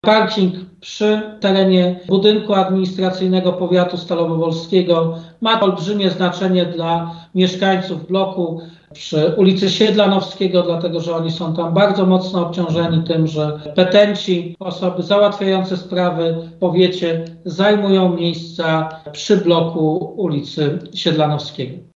O konieczności przebudowy tego parkingu mówił już w 2021 roku prezydent Stalowej Woli Lucjusz Nadbereżny: